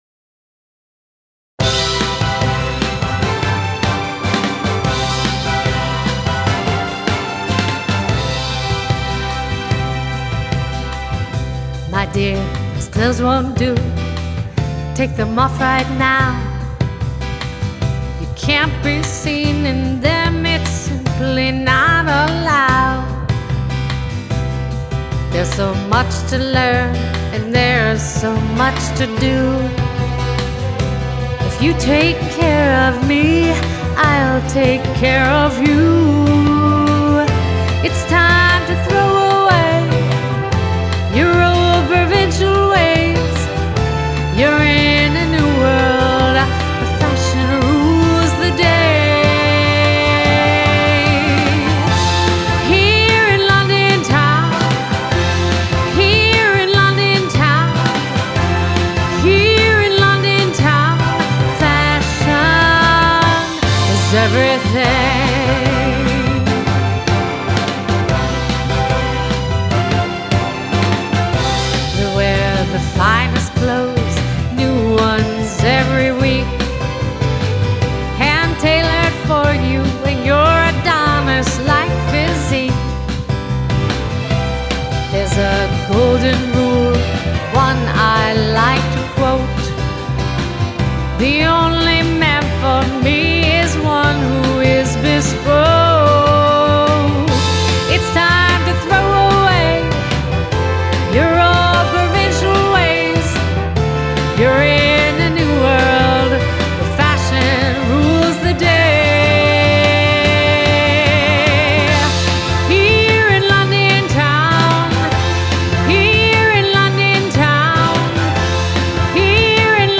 I wanted a lush sound, as if Burt Bacharach arranged them.
We ended up moving the key one step up.
And here’s the demo we did in NYC: